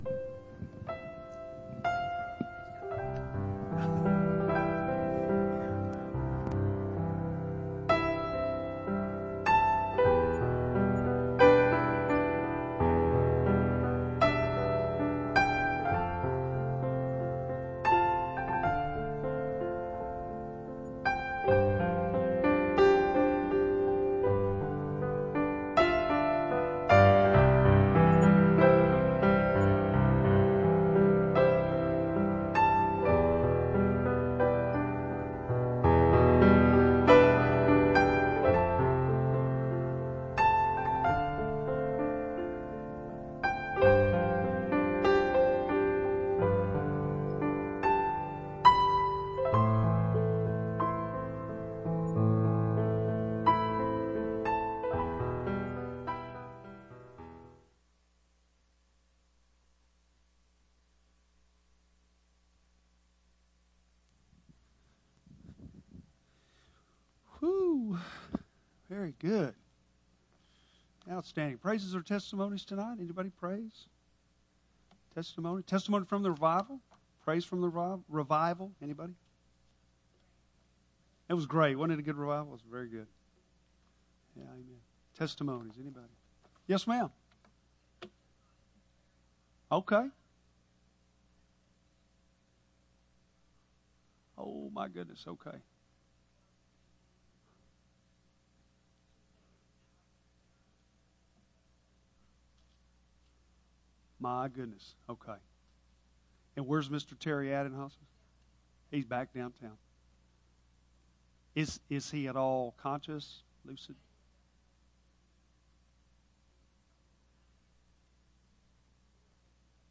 Bible Text: Revelation 9:1-12 | Preacher